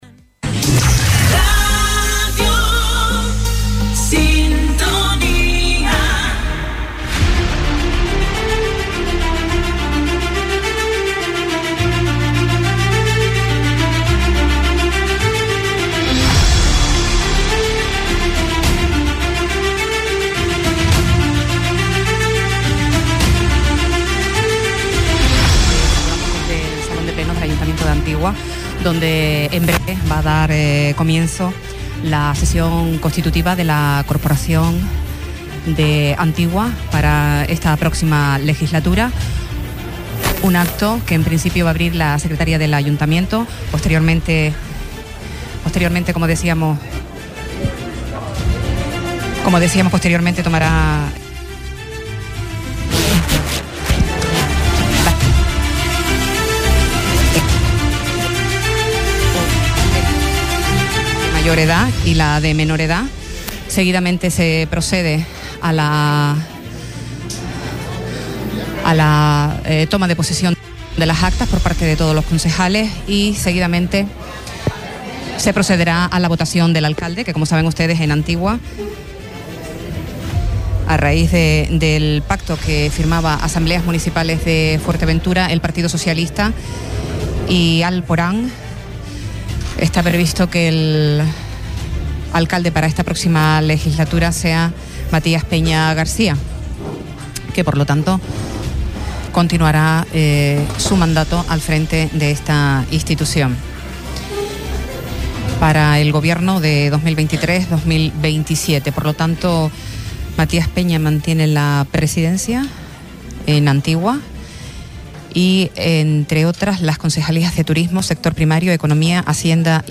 Sesión plenaria para la constitución de la Corporación municipal en el Ayuntamiento de Antigua Deja un comentario